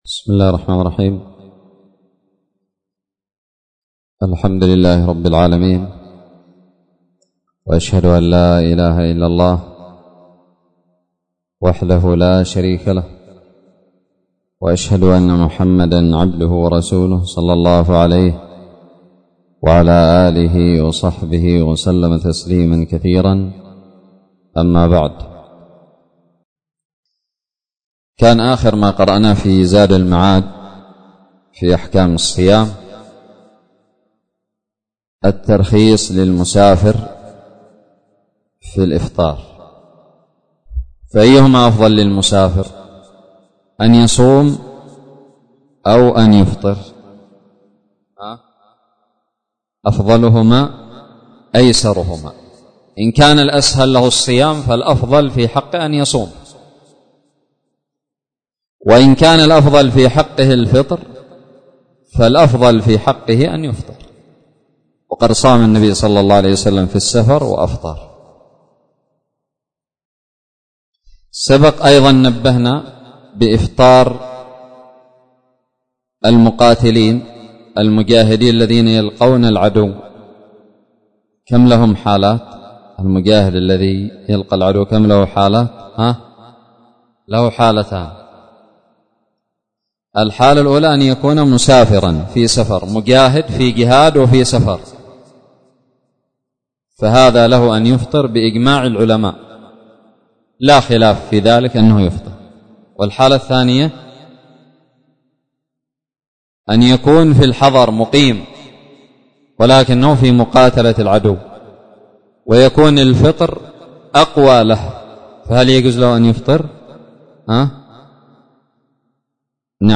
الدرس السابع من التعليق على فصل هدي النبي صلى الله عليه وسلم في الصوم من زاد المعاد
ألقيت بدار الحديث السلفية للعلوم الشرعية بالضالع